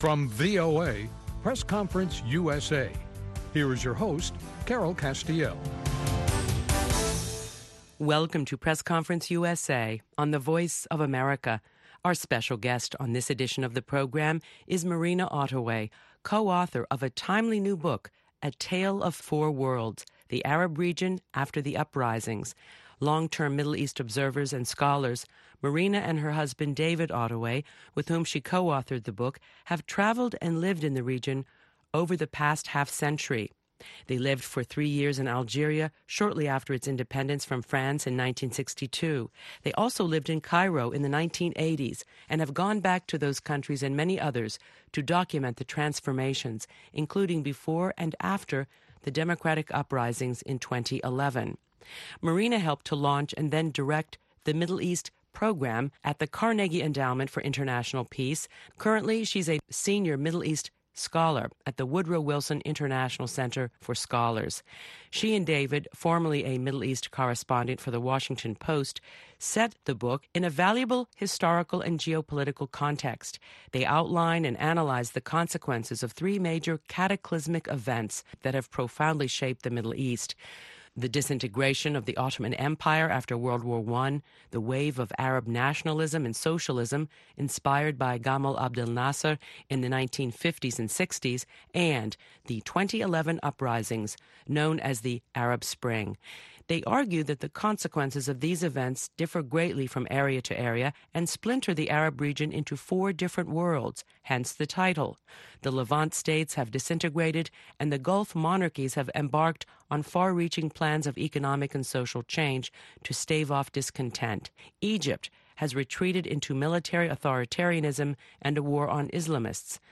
A Conversation with Middle East Scholar